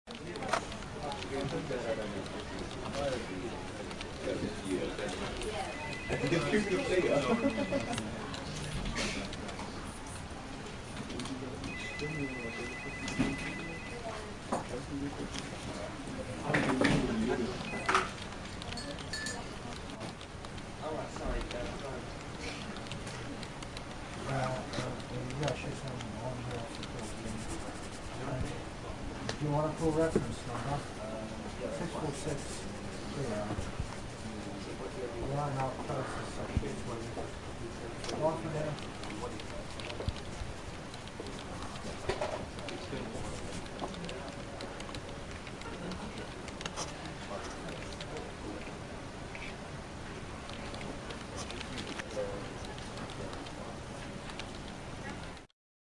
Download Office Ambience sound effect for free.
Office Ambience